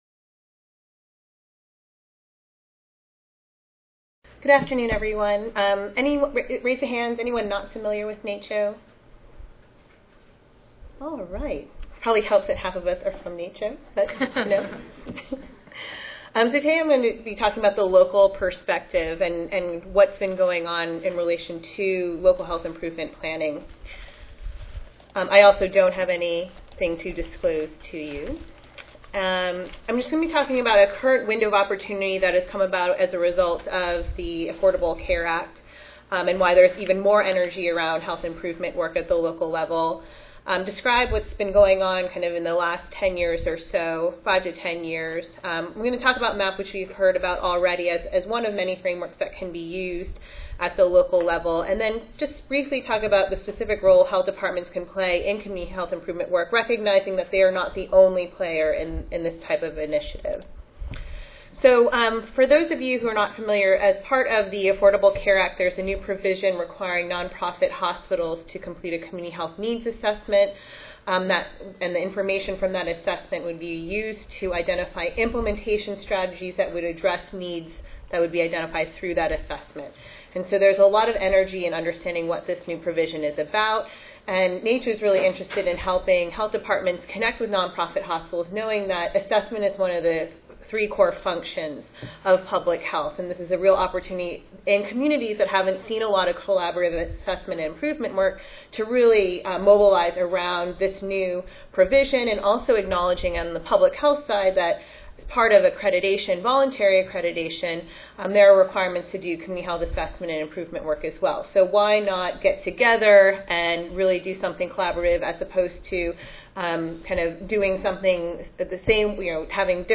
Presenters will share data describing health improvement activity in health departments.